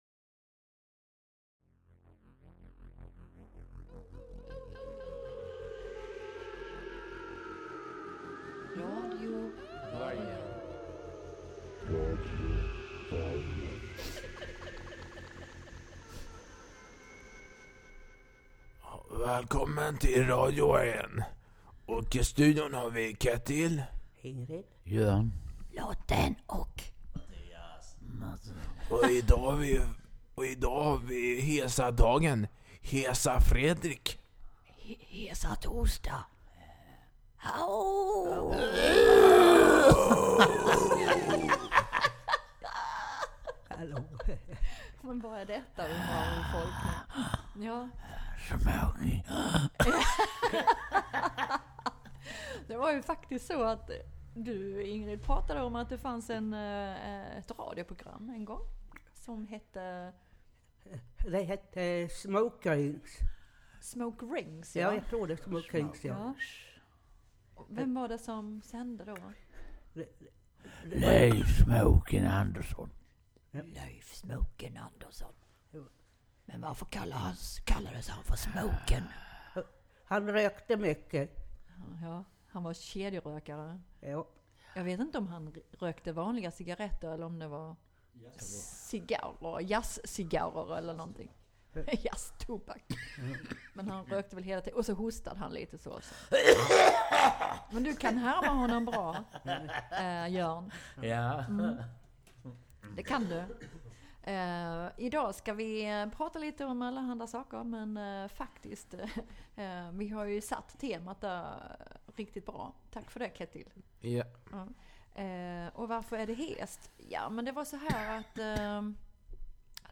I dagens program har vi besök i studion.